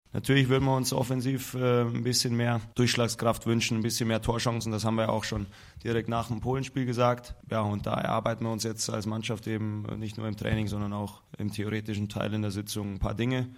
توماس مولر : لاعب المنتخب الألماني